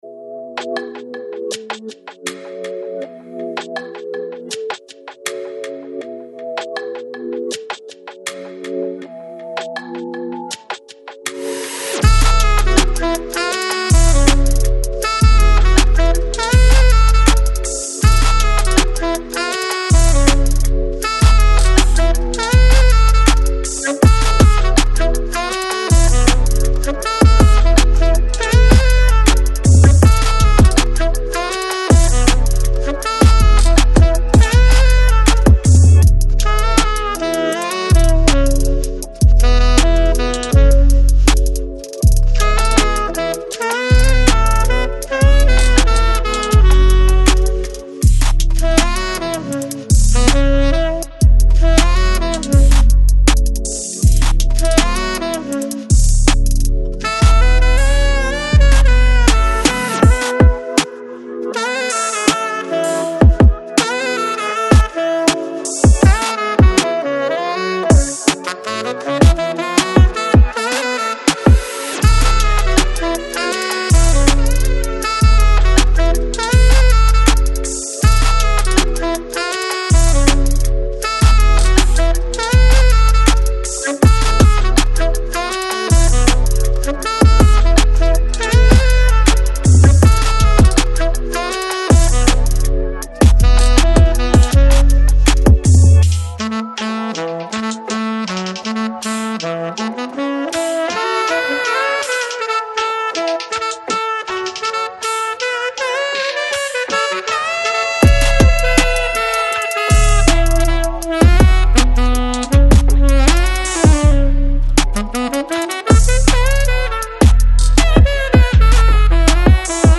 Smooth Jazz, Contemporary Jazz